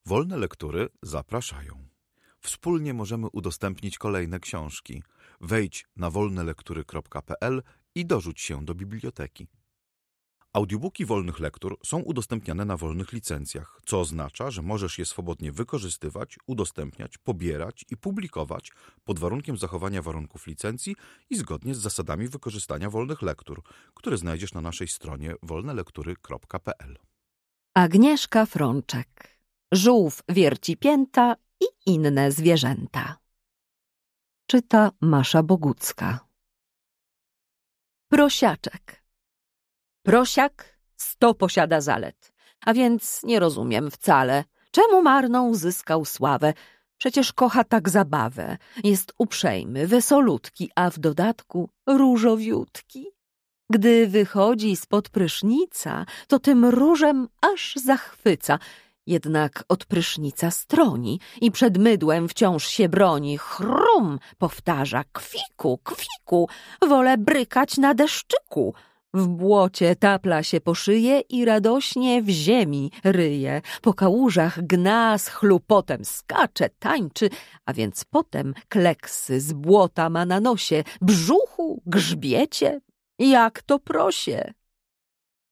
Wiersz
Audiobook